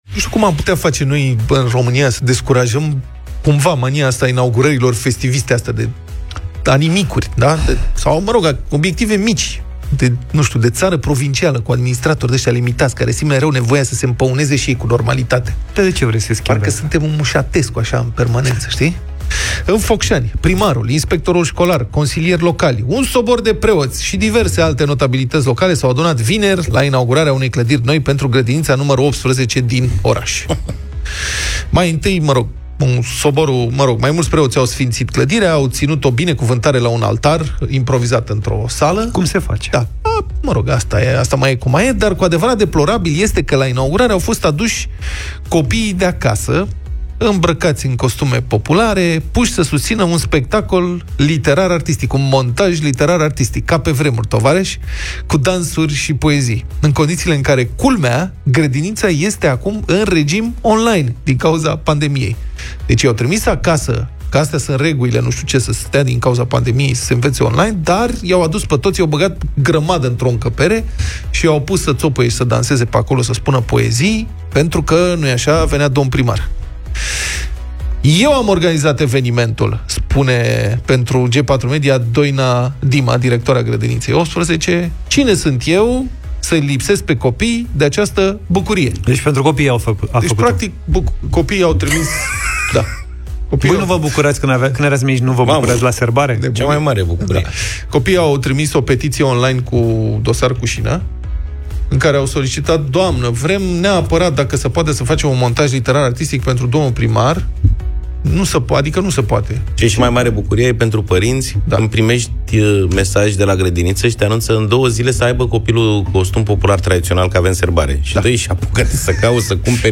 au vorbit despre acest subiect în Deșteptarea